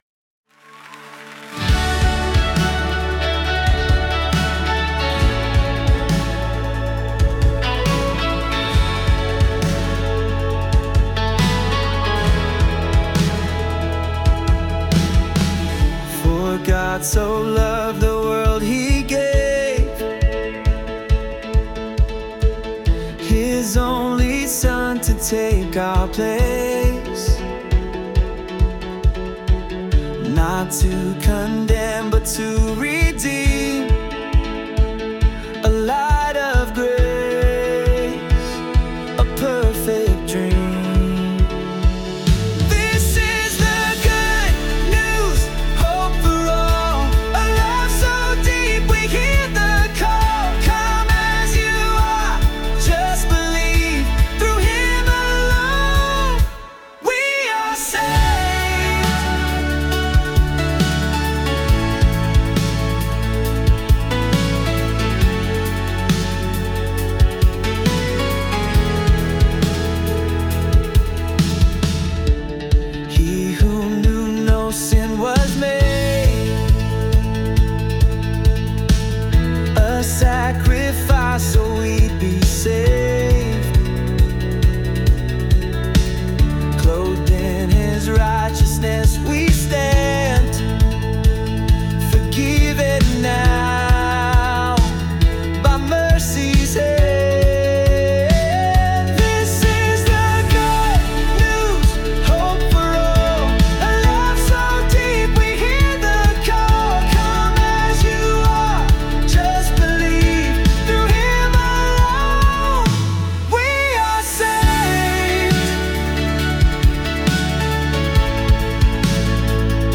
Christian Worship